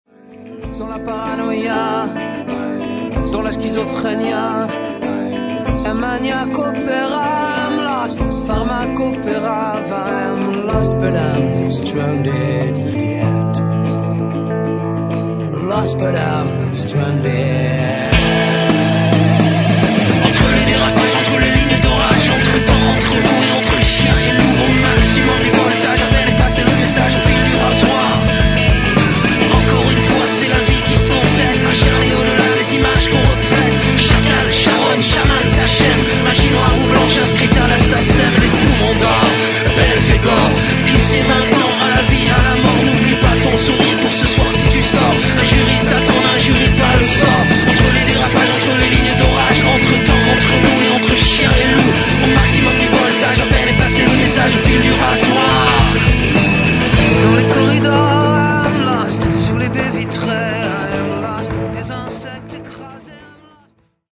clavier